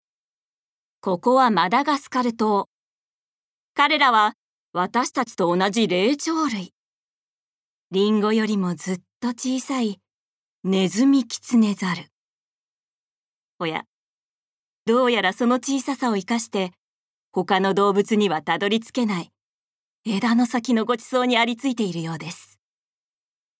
ボイスサンプル
ナレーション４